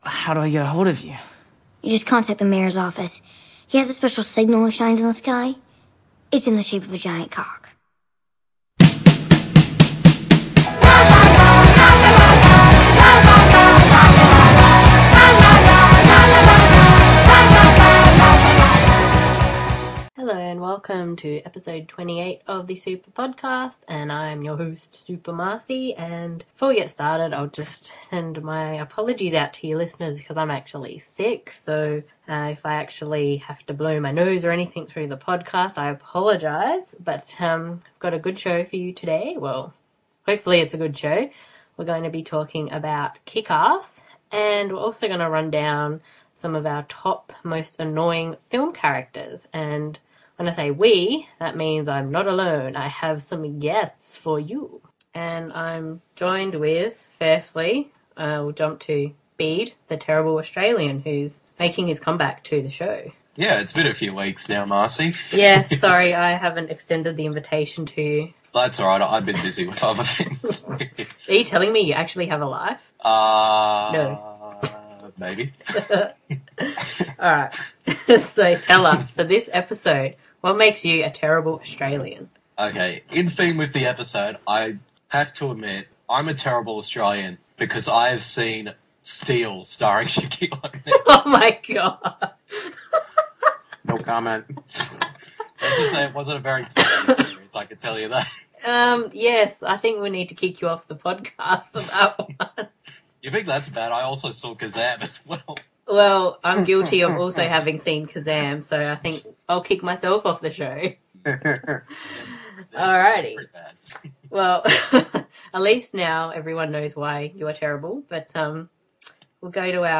As usual I would like to thank my three guests for joining me for the show, and for putting up with me being rather sick (excuse my nose blowing haha)!